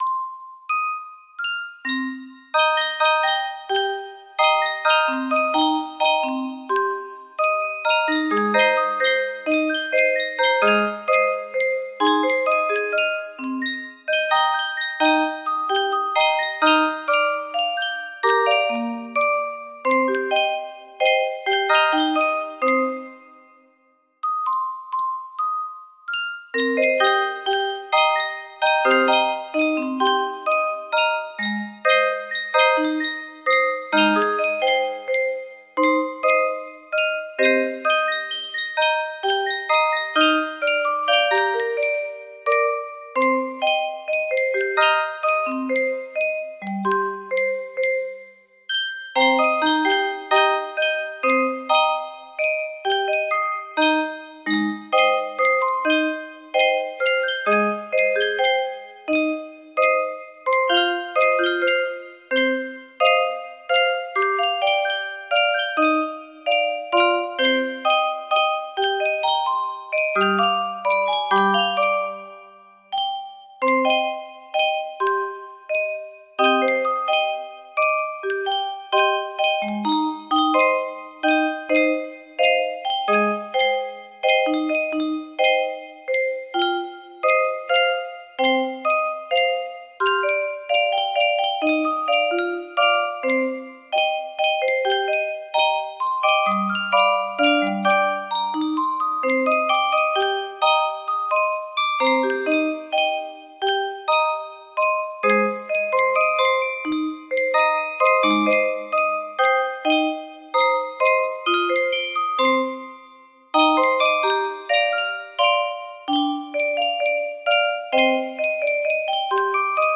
Full range 60
Traditional Japanese stringed musical instrument